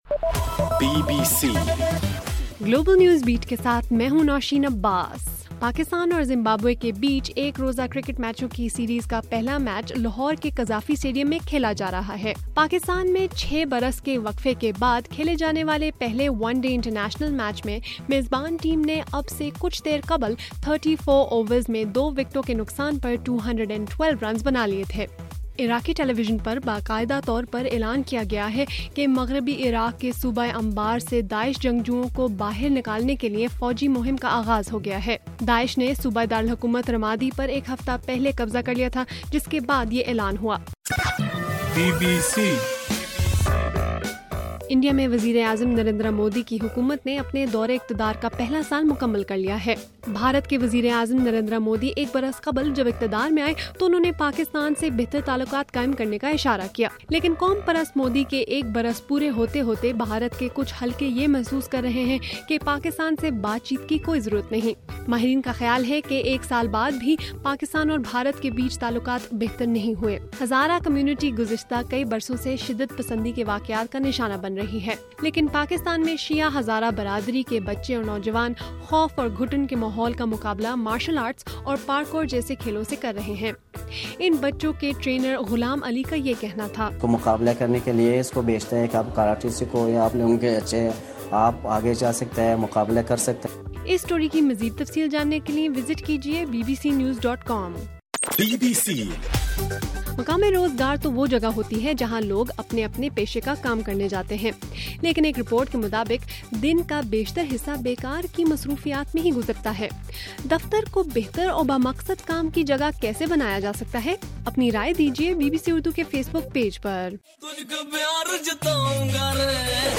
مئی 26: رات 8 بجے کا گلوبل نیوز بیٹ بُلیٹن